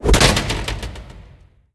doorSlam.ogg